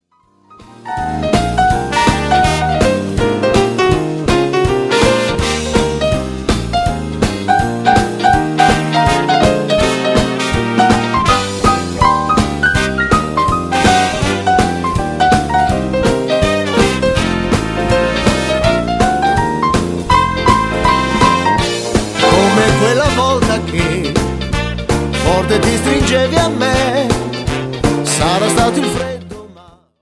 FOX - TROT  (2.49)